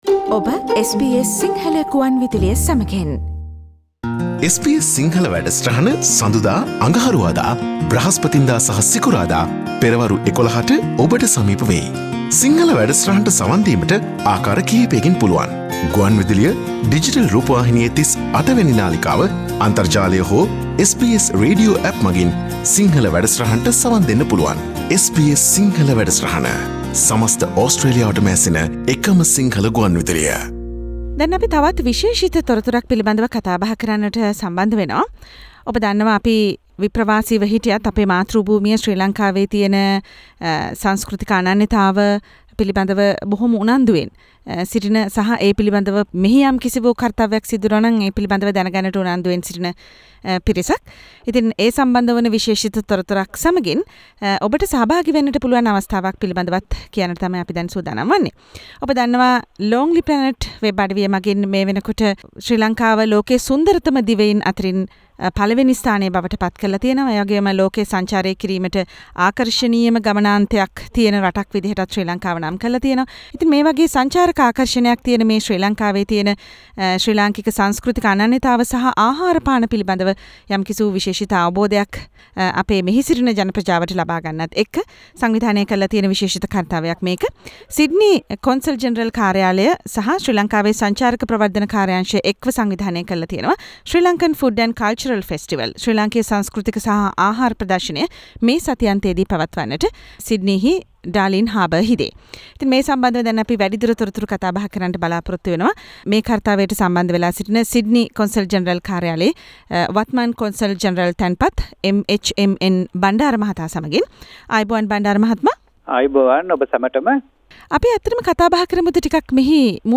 Consul General of Sydney Consular Office Mr. M.H.M.N Bandara joined the SBS Sinhalese service to discuss the Sri Lankan Food and Culture Festival at Darling Harbor which is to be held this weekend together with Sri Lanka Tourism Promotion Bureau.